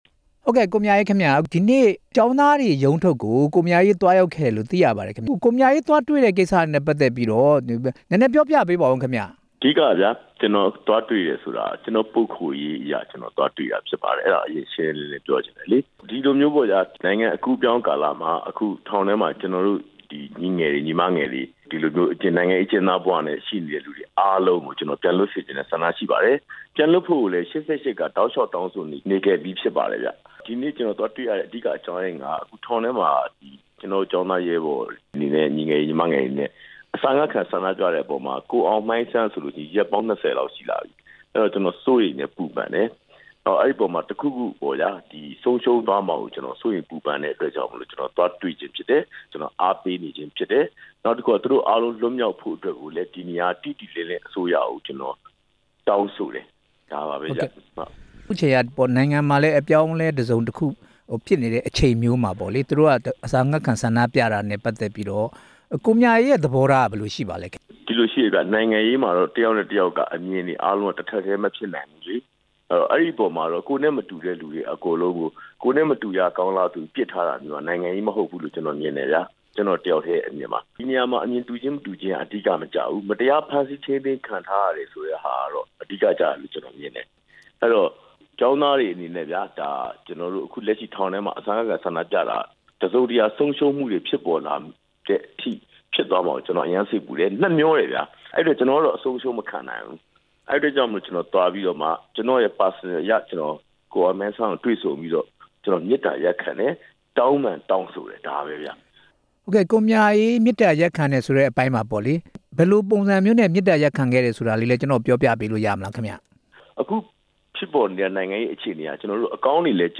ကိုမြအေးနဲ့ မေးမြန်းချက်